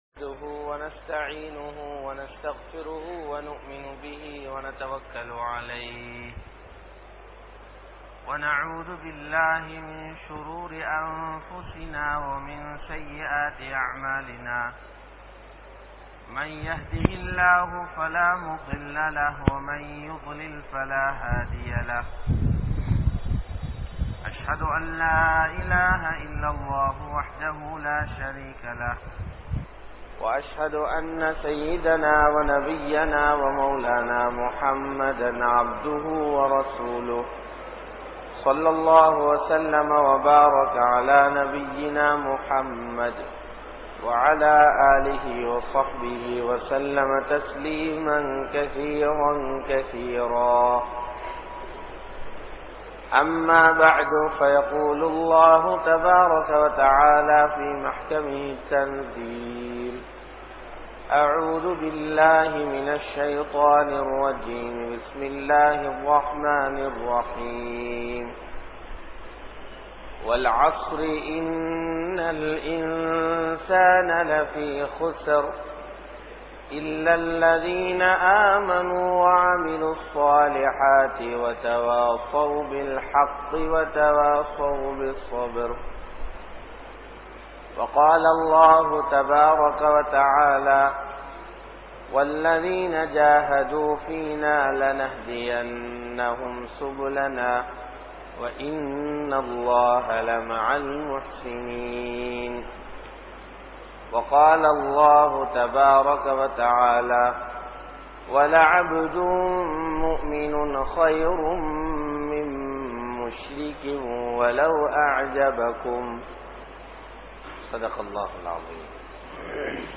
Ullaththil Ulaippu Seiungal (உள்ளத்தில் உழைப்பு செய்யுங்கள்) | Audio Bayans | All Ceylon Muslim Youth Community | Addalaichenai